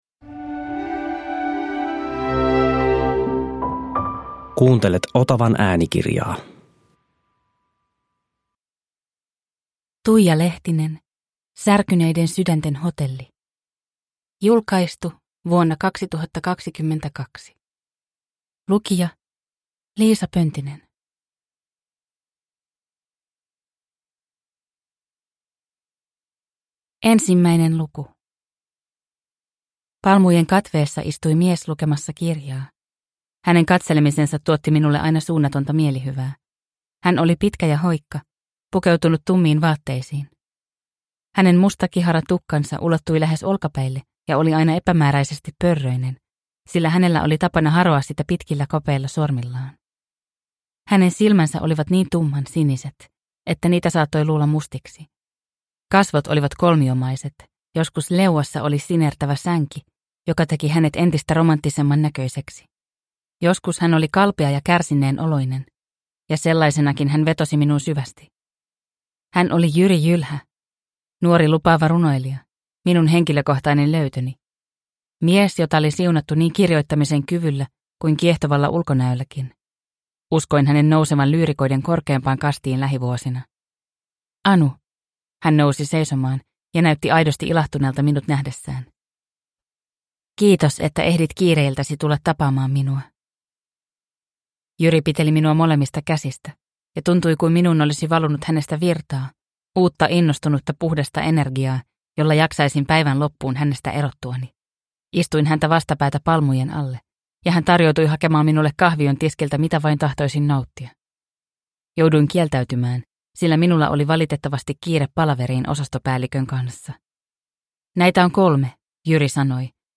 Särkyneiden sydänten hotelli – Ljudbok – Laddas ner